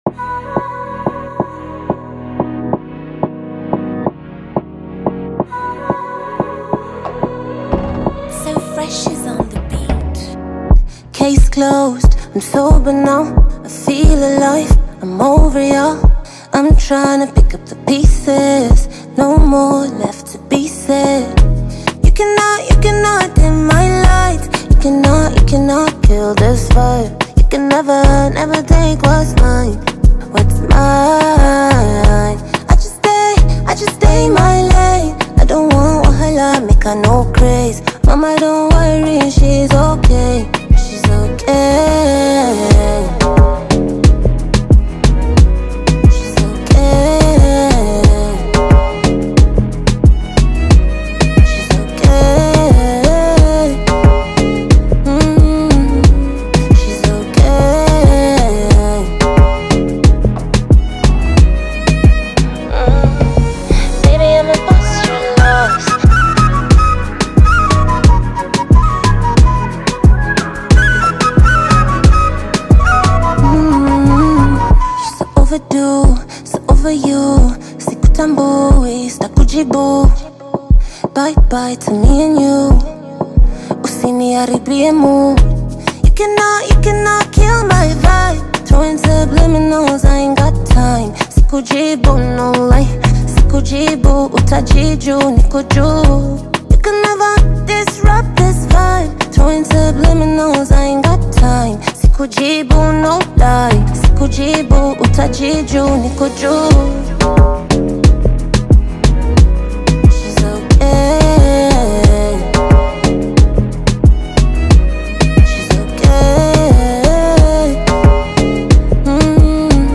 Afrobeat and pop music
strong vocals and engaging performance